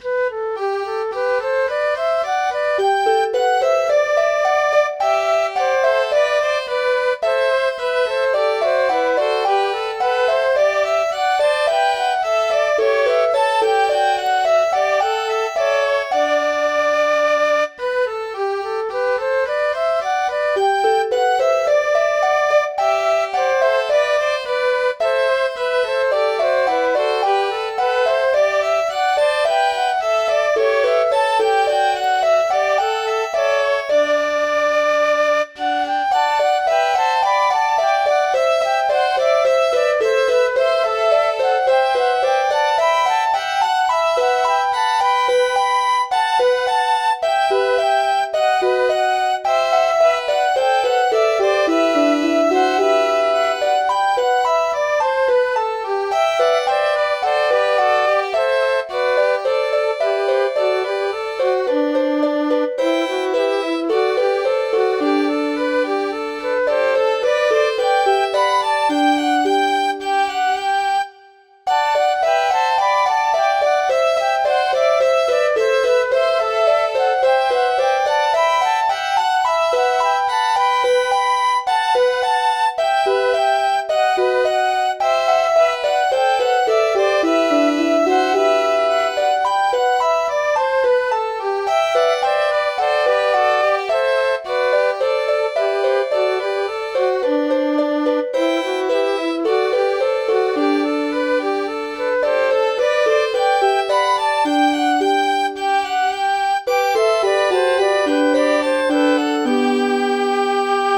tunes in Midi Format